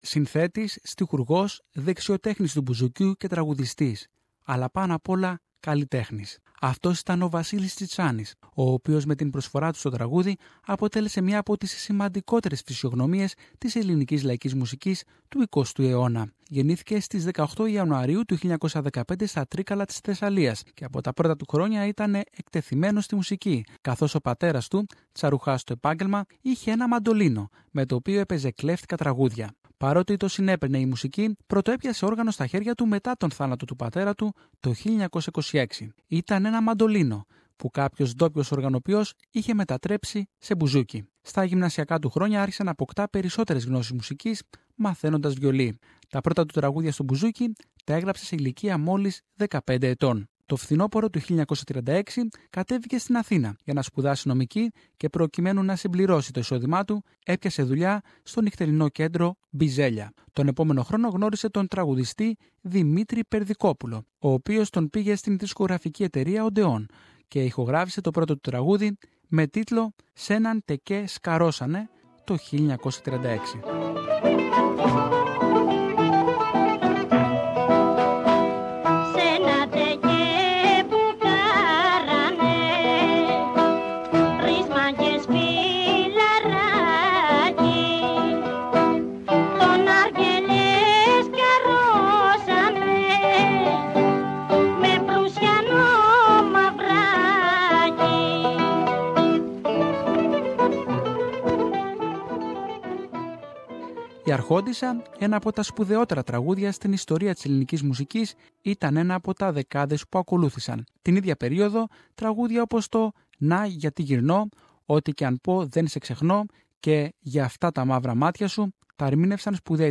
Vassilis Tsitsanis, the great Greek songwriter and bouzouki player, was one of the leading Greek composers of Greek and world music. Tsitsanis wrote more than 1000 songs and he will always be remembered as an extraordinary composer and bouzouki player. More about his life in this special report